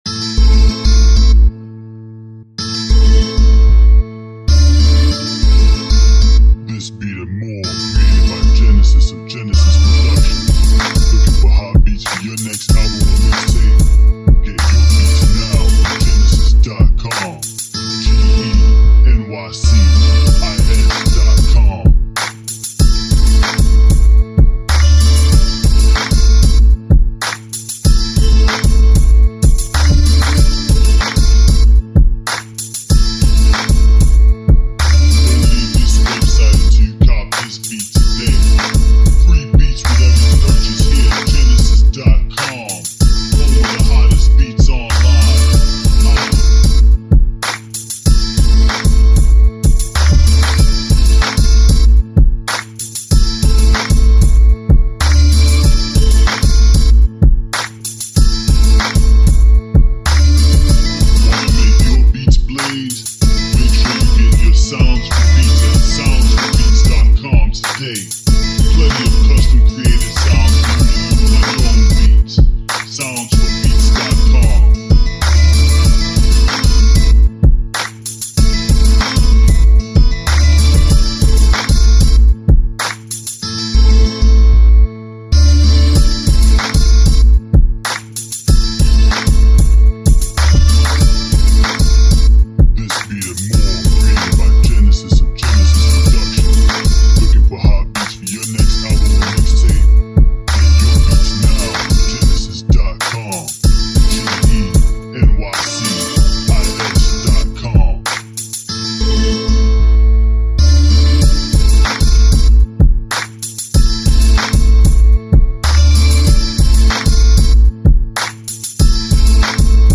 Upbeat Guitar Street Flava Club Beat